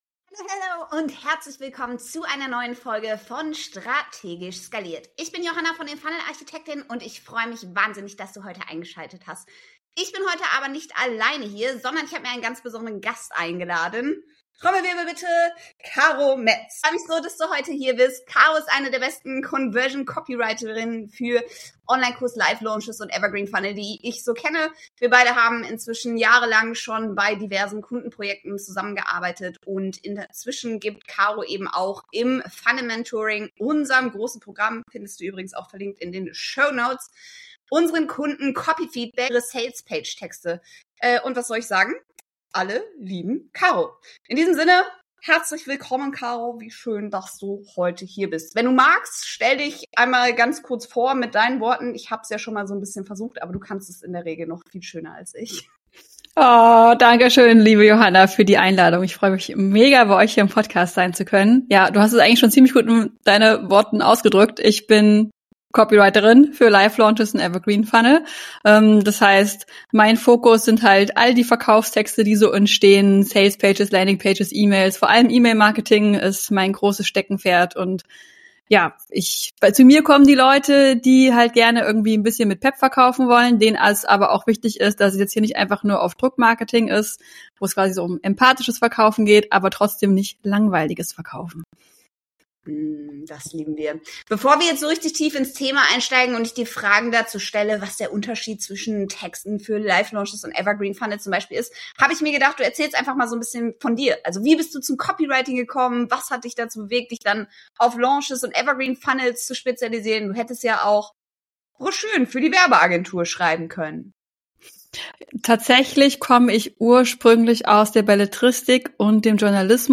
Die beste Copywriterin, die wir kennen verrät dir im Interview, wie du von einer "so lala" zu einer "BÄÄÄM" SalesPage kommst, die am Ende für dich KundInnen gewinnt.